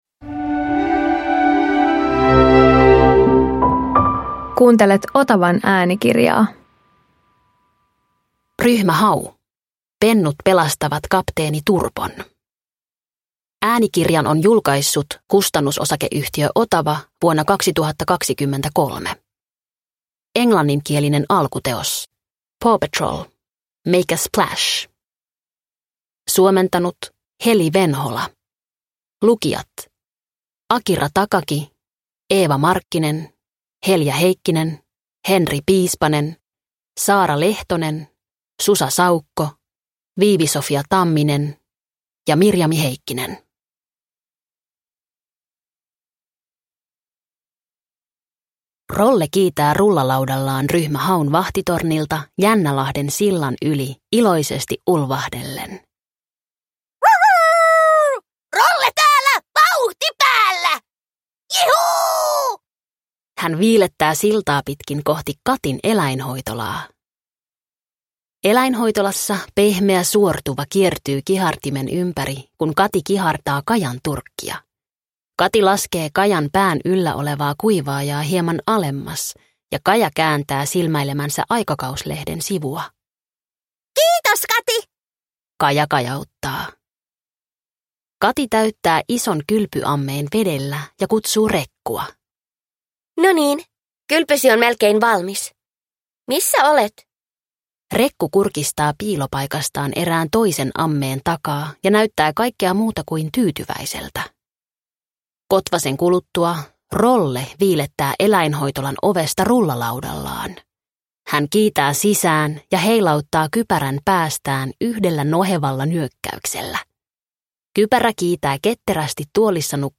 Ryhmä Hau - Pennut pelastavat kapteeni Turpon – Ljudbok – Laddas ner